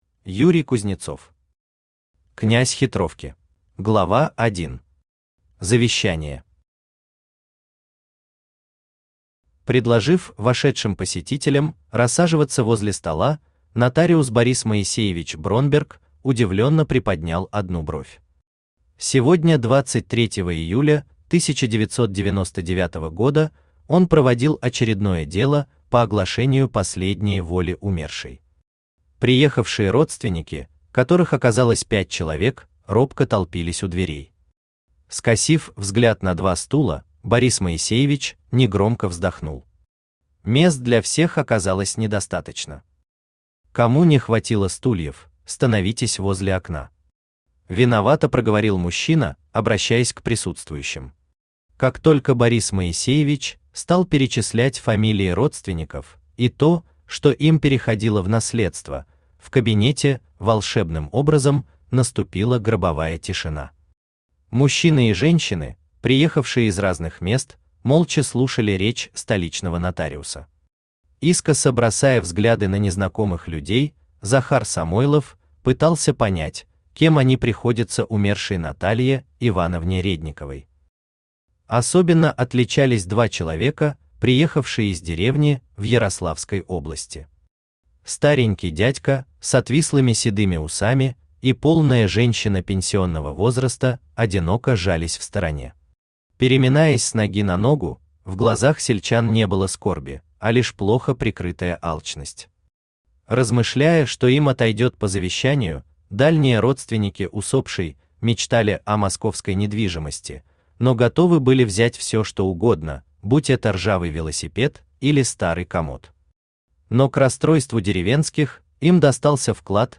Аудиокнига Князь Хитровки | Библиотека аудиокниг
Aудиокнига Князь Хитровки Автор Юрий Юрьевич Кузнецов Читает аудиокнигу Авточтец ЛитРес.